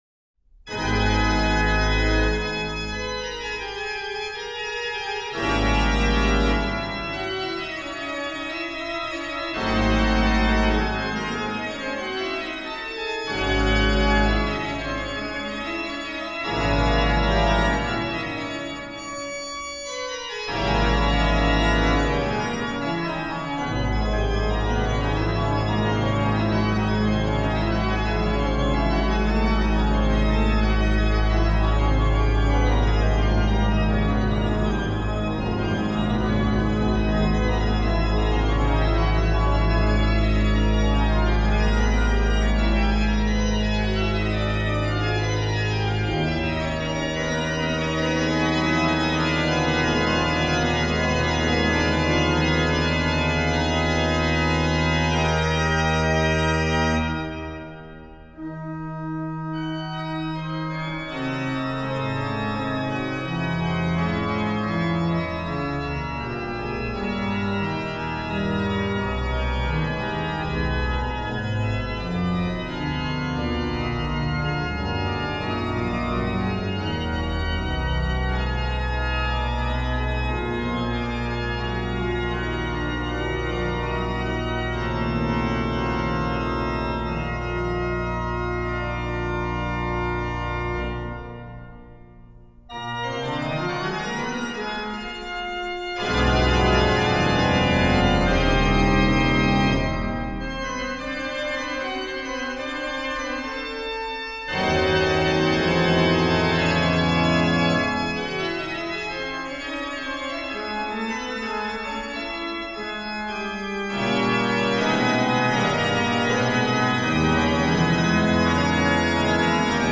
HW: Pr16, Oct8, Ged8, Oct4, Nas3, Mix, Tr16, BW/HW
BW: Fl8, Oct4, Oct2, Scharff, Dulc8, Schal4
Ped: Pr16, Oct8, Oct4, Pos16, Tr8
Pos: Oct8, Rfl8, Oct4, Oct2, Scharff, Fgt16
m. 14: Ped: + Rausch, Mix, Pos16